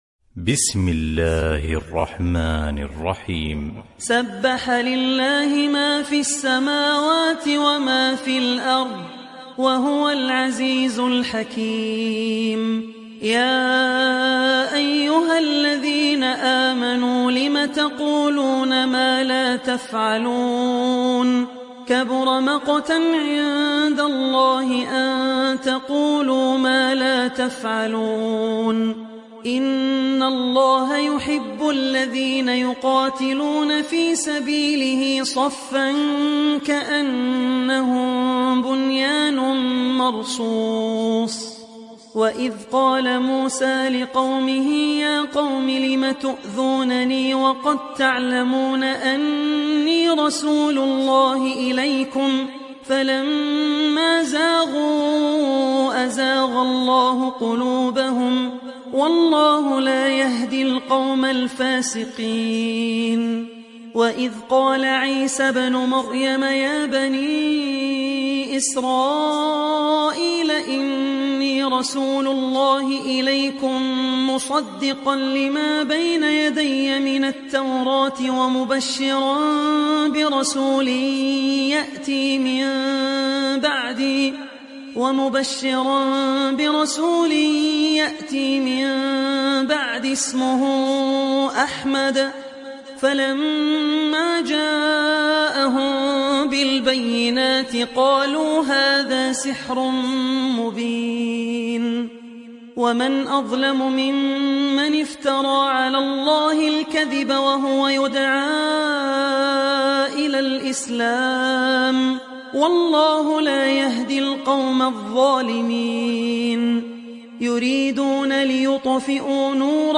Saf Suresi İndir mp3 Abdul Rahman Al Ossi Riwayat Hafs an Asim, Kurani indirin ve mp3 tam doğrudan bağlantılar dinle